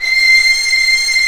55m-orc15-C6.wav